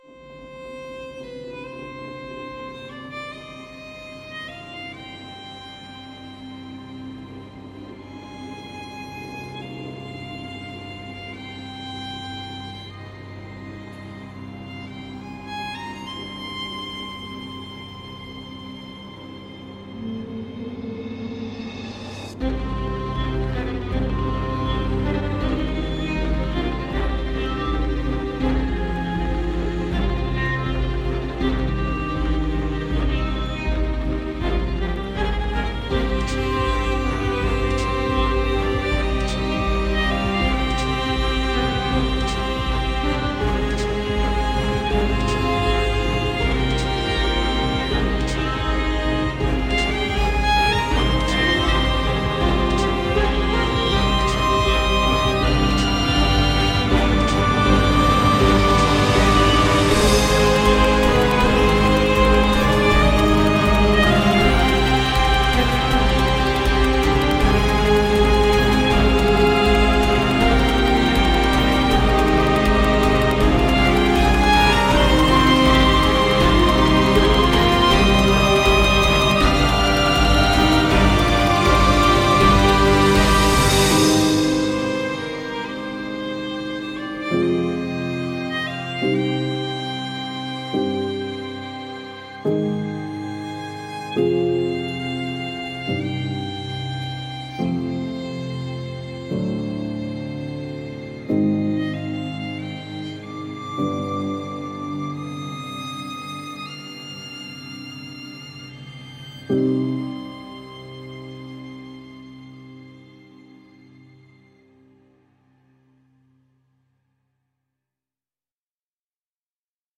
它充满了灵魂、情感和原始的特色。
- 9.7 GB 的未压缩数据，包括多个麦克风位置（近距离单声道，近距离立体声，中距离，远距离，和混合）。
- 54 个预设，涵盖了各种音色和技法，如暗色持续音，颤音，泛音，滑音，颤抖，颤音滑音，和弹奏。
包含了暗色持续音的完整补丁。
Sonixinema-Contemporary-Soloist-Violin.mp3